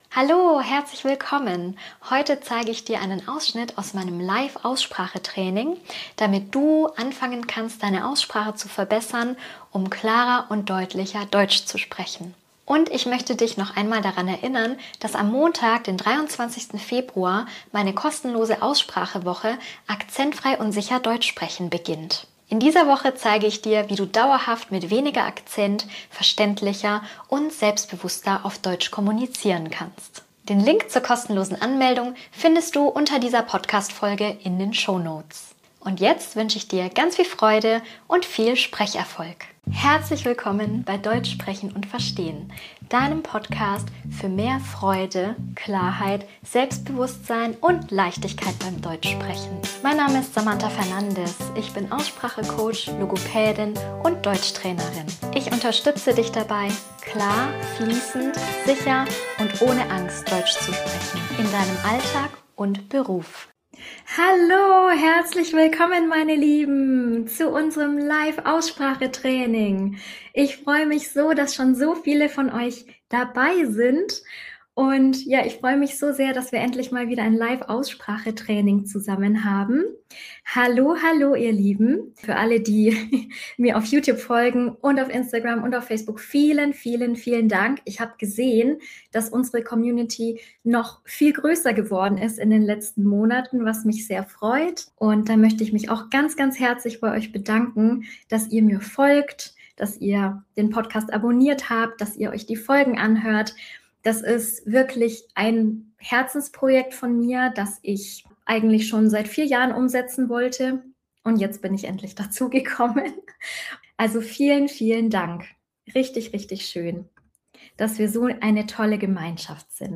Beschreibung vor 1 Monat In dieser Folge hörst du einen echten Ausschnitt aus meinem Live-Aussprache-Training. Wir üben gemeinsam, wie du klar, deutlich und sicher Deutsch sprichst, in deinem Alltag und Beruf.